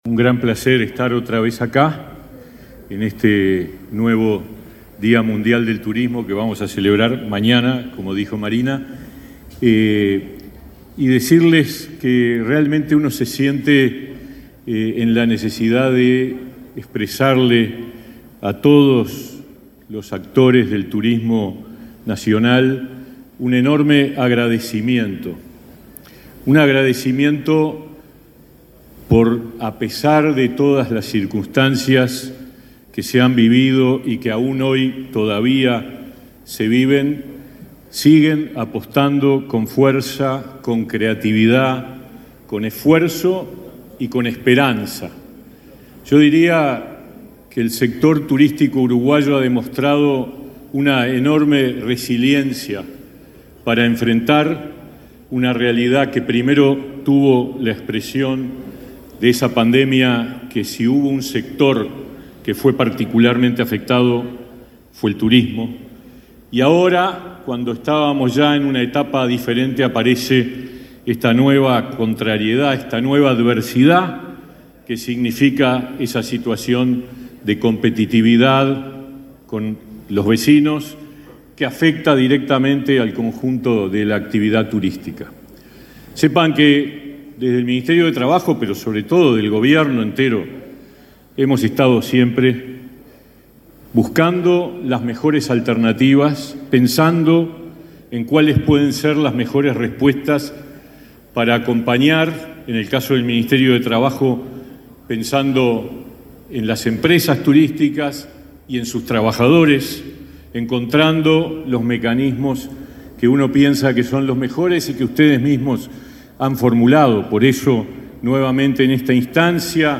Palabra de los ministros de Trabajo y Turismo
Palabra de los ministros de Trabajo y Turismo 26/09/2023 Compartir Facebook X Copiar enlace WhatsApp LinkedIn El ministro de Trabajo, Pablo Mieres, y su par de Turismo, Tabaré Viera, participaron, este martes 26 en la chacra La Redención, de la celebración del Día Mundial del Turismo.